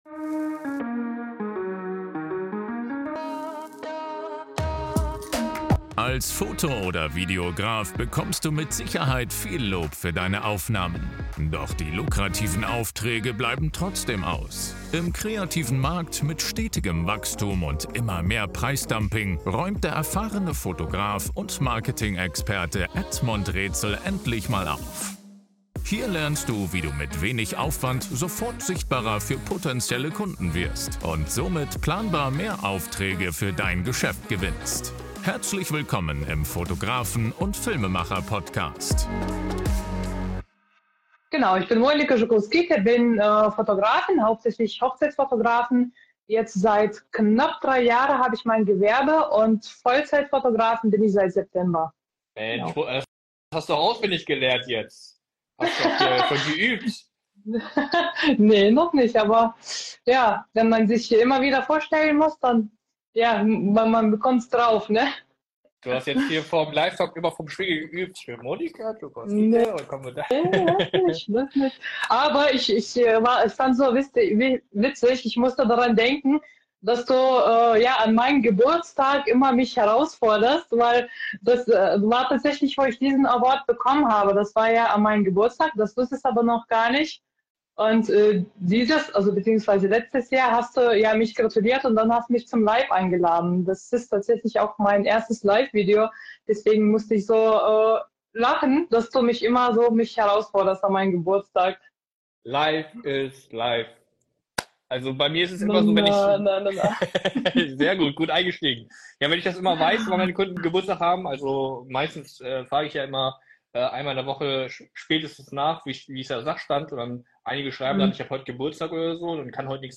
In diesem Interview berichtet sie über ihre Hürden auf dem Weg, über effektive Verbesserung der Qualität als Quereinsteiger und viele spannende Erlebnisse aus ihren Shootings.